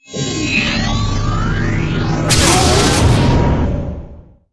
DefaultEnterWarp.wav